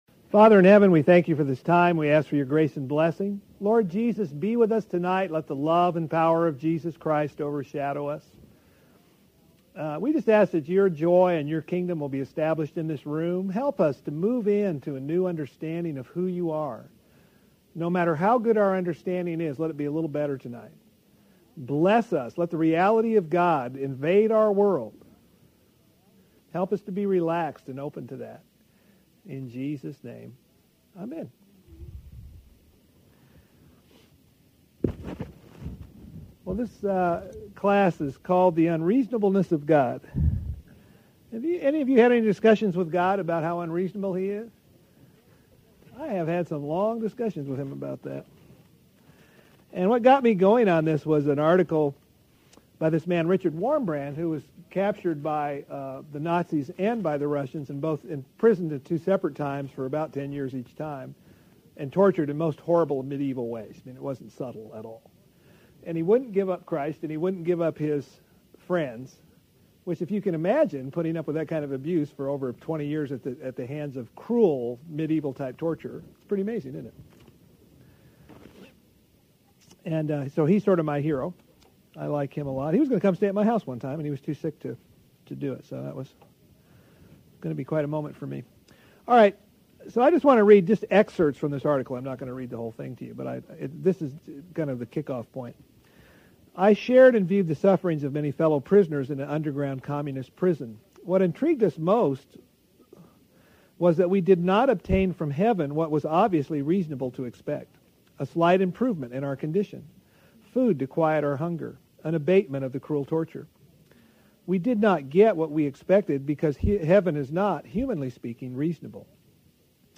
Lesson 1: School of the Ox